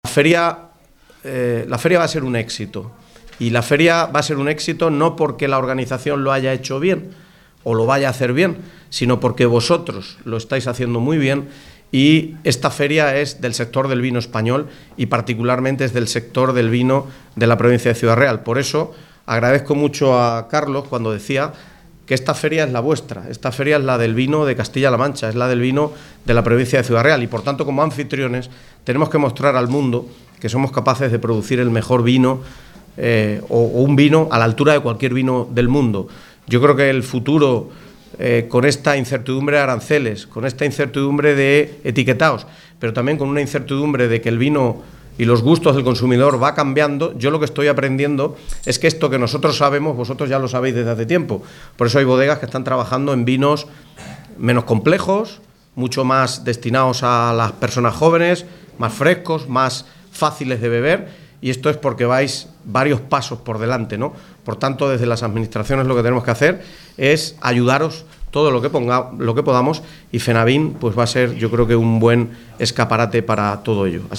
Rueda de prensa en el interior de FENAVÍN
Pte.-Diputacion-de-Ciudad-Real-Miguel-Angel-Valverde.mp3